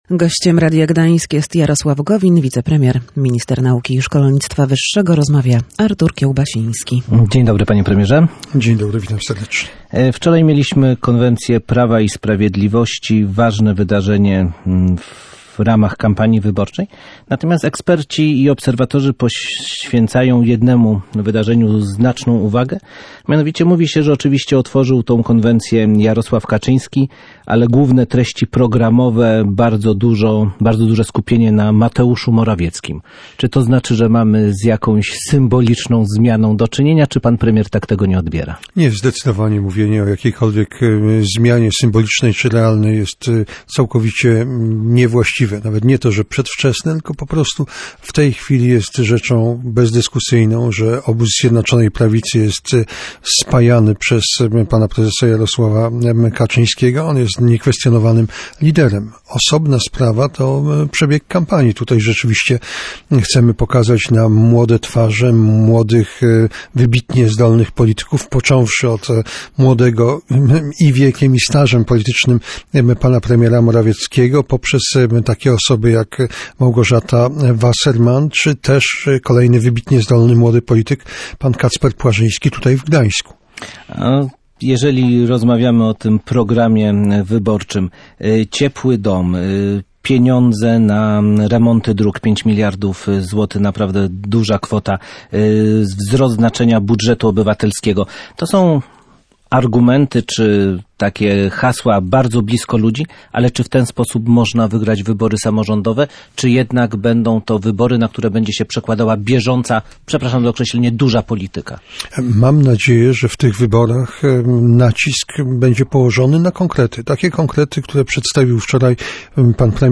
Wicepremier mówił w Radiu Gdańsk, że liczy się przede wszystkim zrównoważony rozwój.